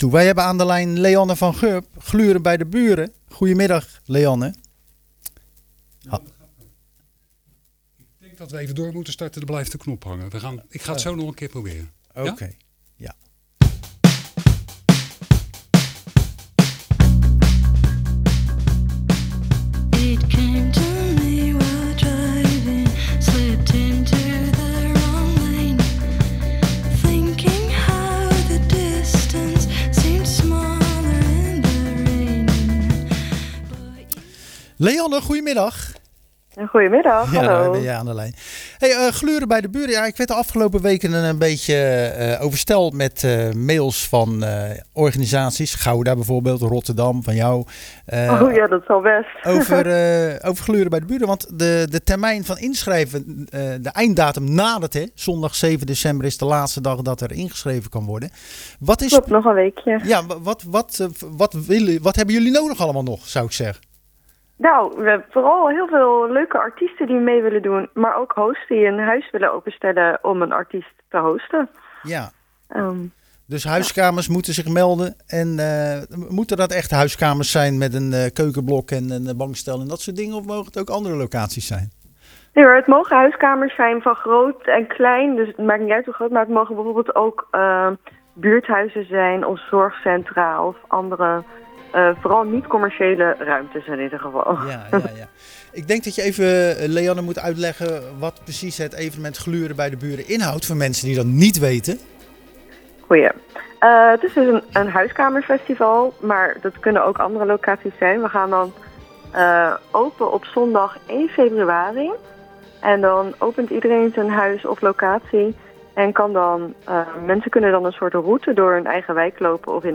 Tijdens de uitzending van Zwaardvis belden we met